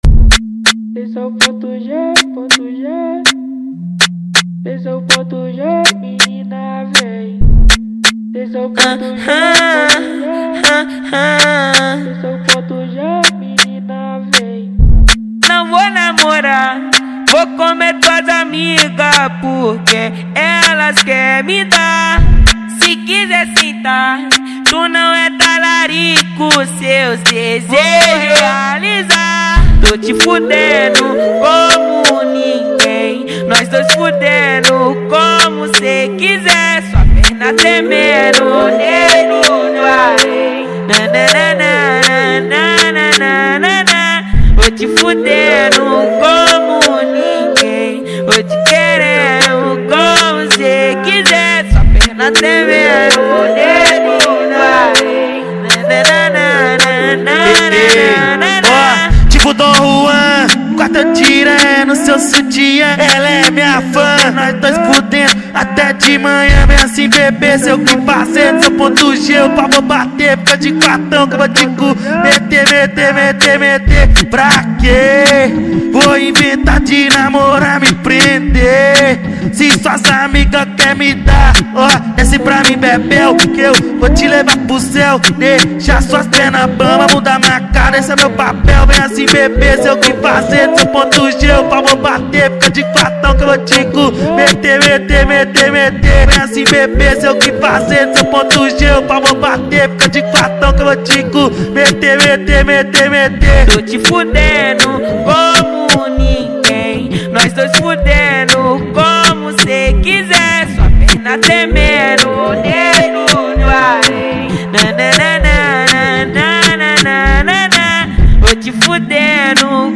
Gênero: Phonk